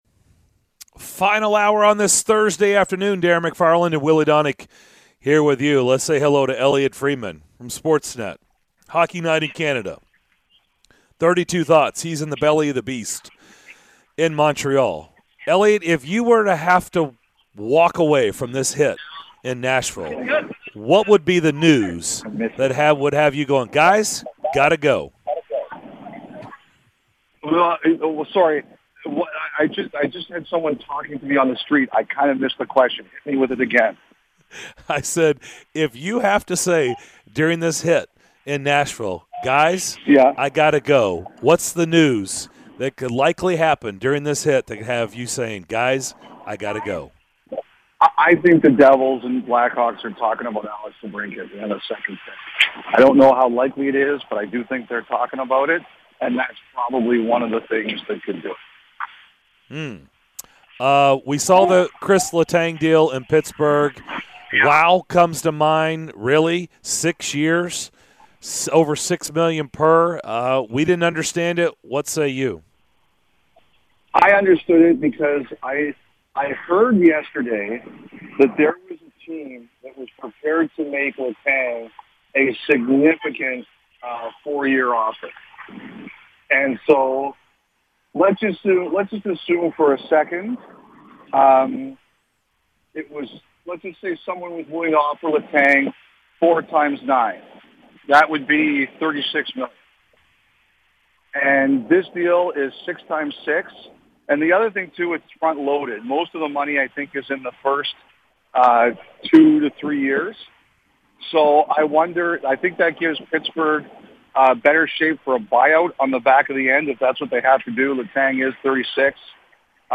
Elliotte Friedman Full Interview (07-07-22)